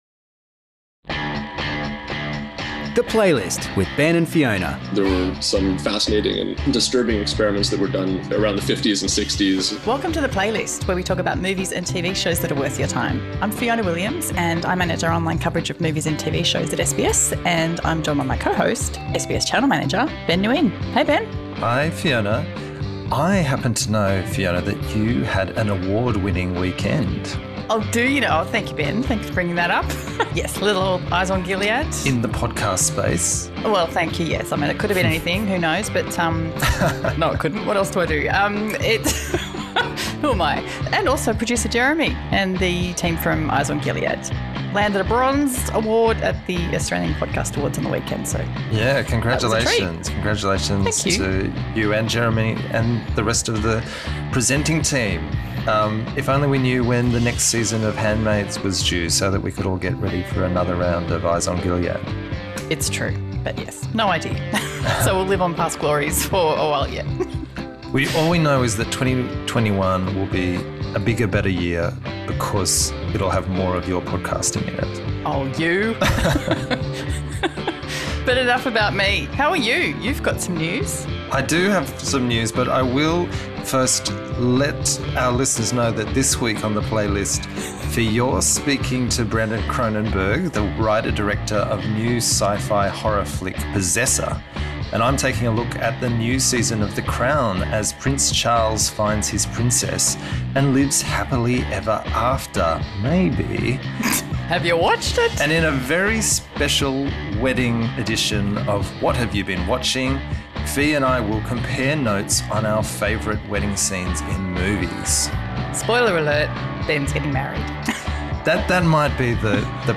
The Playlist 137 / Interview with Brandon Cronenberg / 'The Crown' / Wedding Movies